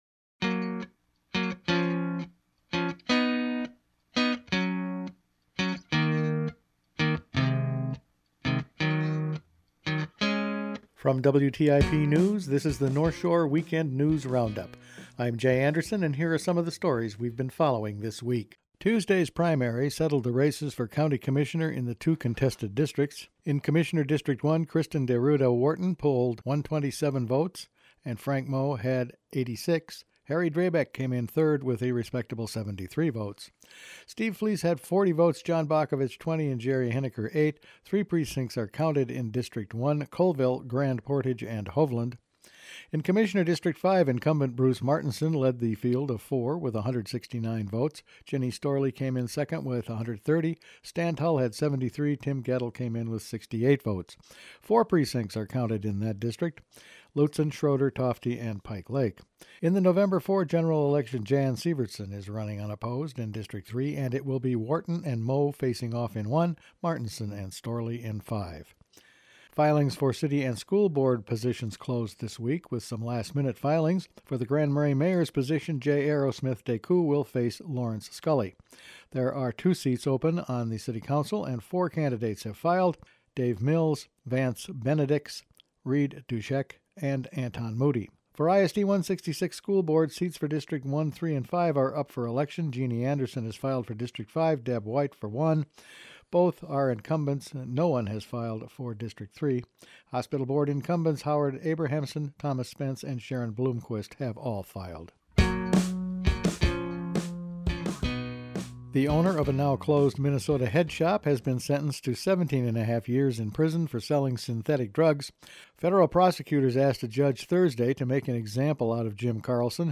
Weekend News Roundup for August 16